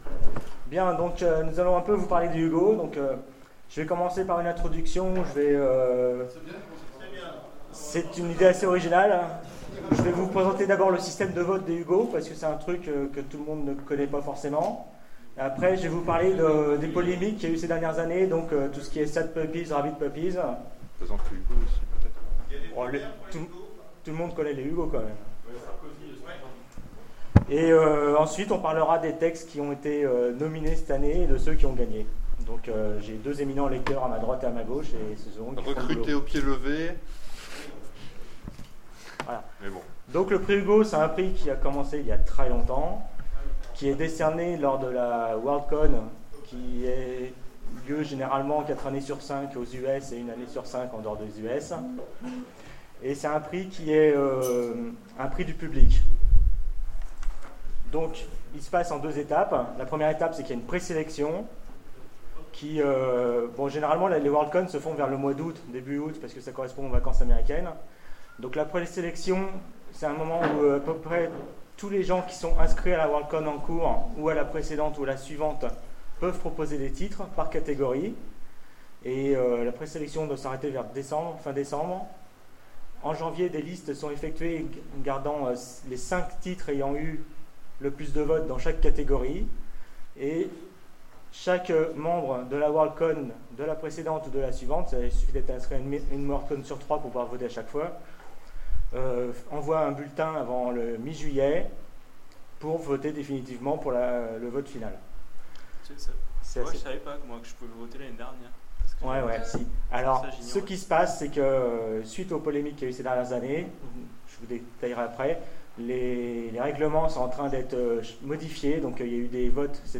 Convention SF 2016 : Conférence Le prix Hugo